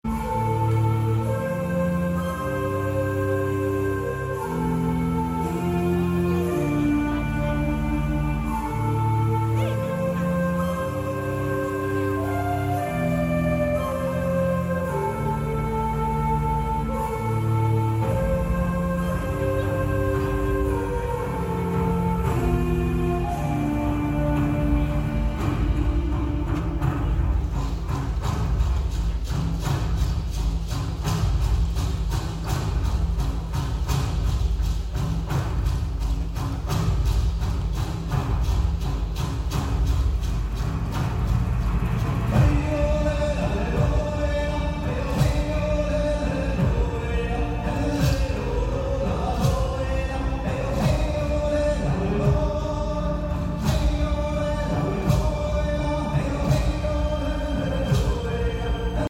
en Concierto, Haikou China